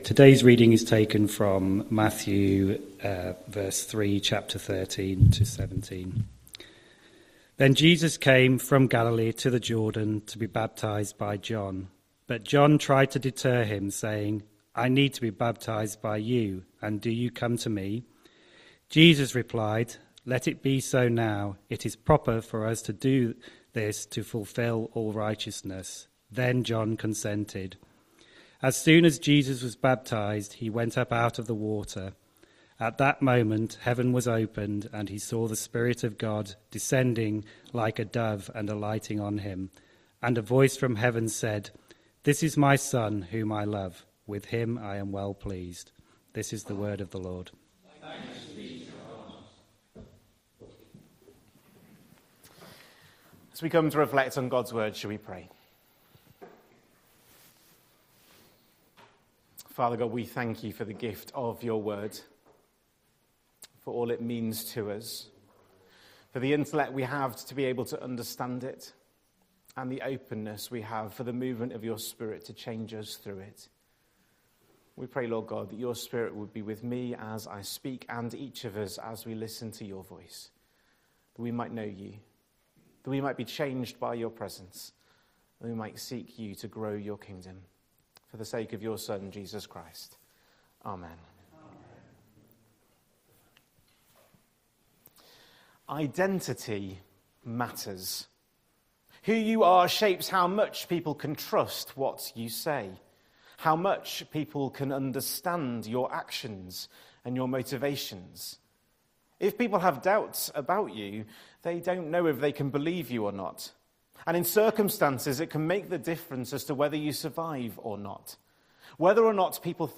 11th January 2026 Sunday Reading and Talk - St Luke's